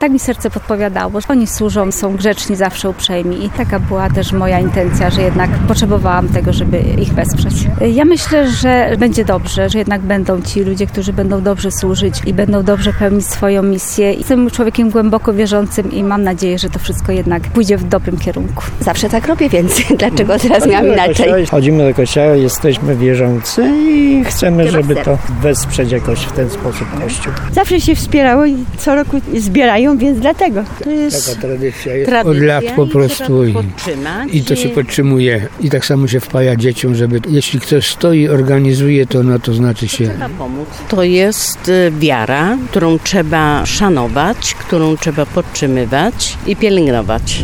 Na cmentarzu parafialnym w Wojniczu darczyńcy podkreślali, że poprzez wpłaty do puszek chcą wspierać Kościół.